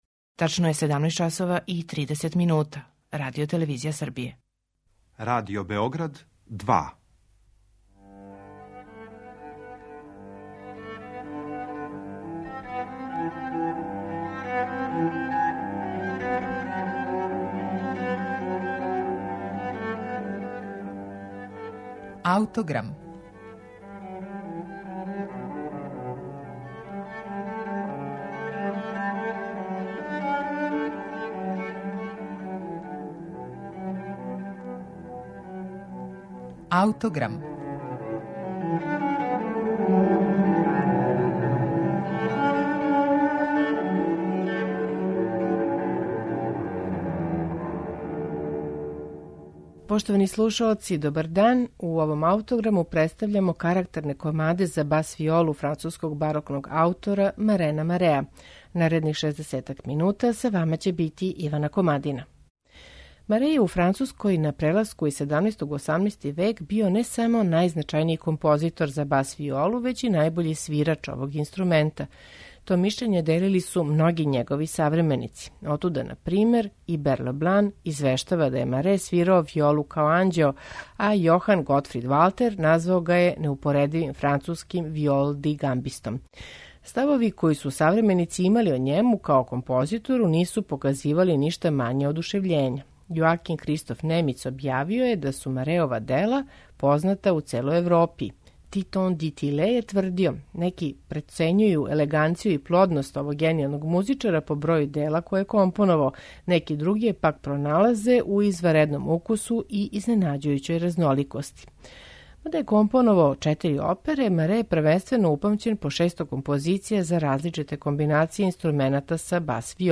кратка, живописна дела
виола да гамба
теорба и гитара
харпсикорд
удараљке